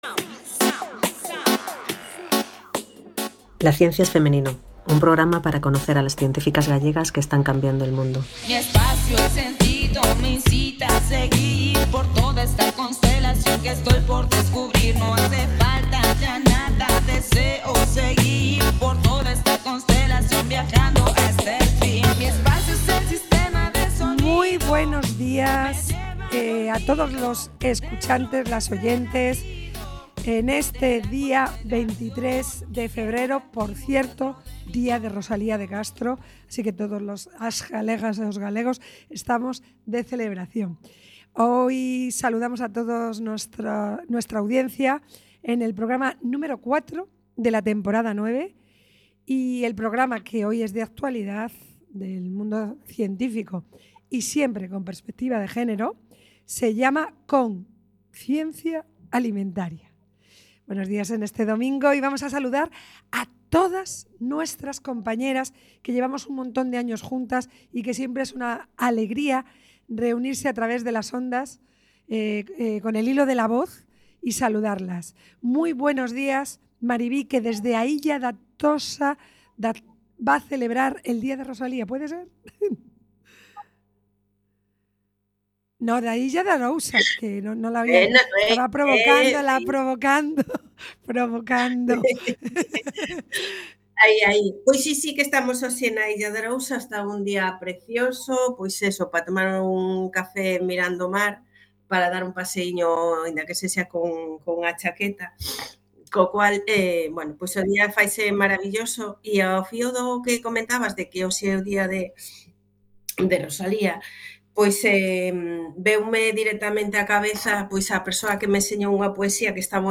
En este programa de radio, cada 15 días, durante una hora, de 12.00 a 13.00, en domingos alternos, Entrevistamos y damos voz a las científicas gallegas que trabajan hoy en la ciencia.
Localizaciones: Estudio José Couso- Cuac FM.